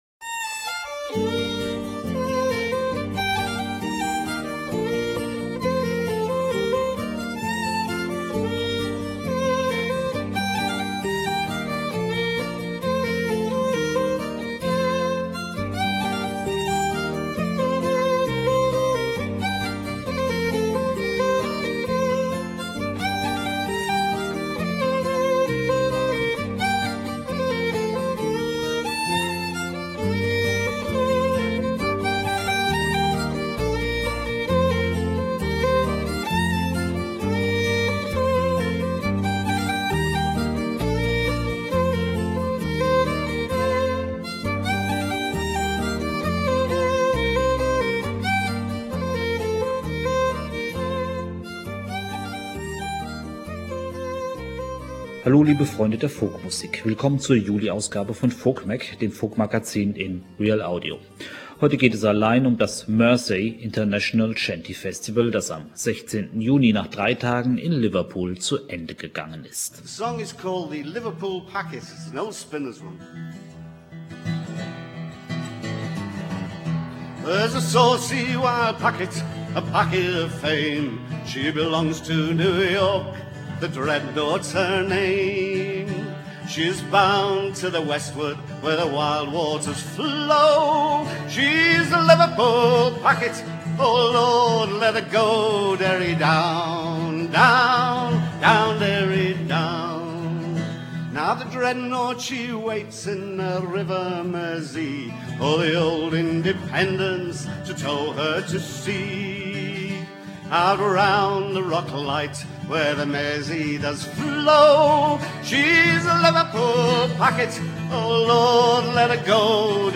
Shanty Festival Liverpool 2002